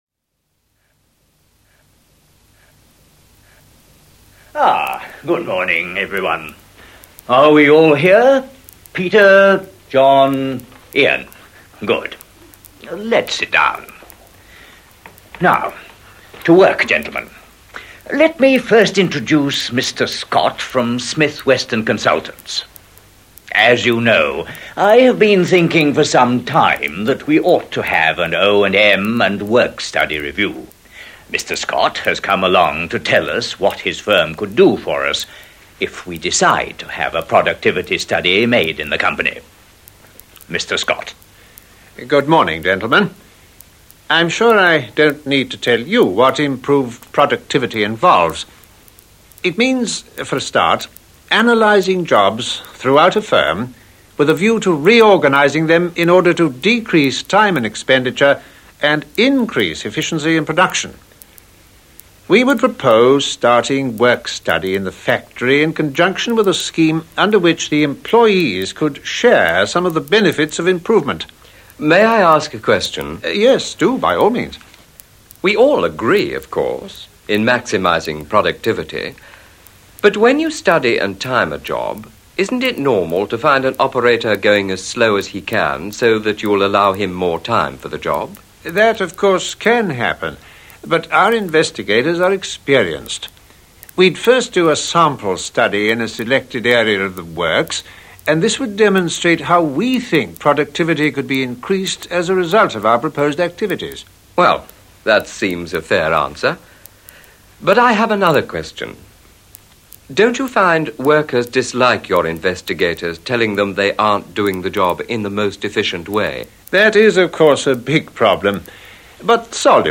conversation11.mp3